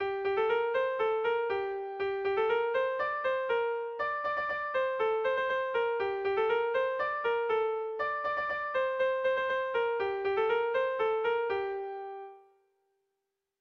Erromantzea
A1A2B1B2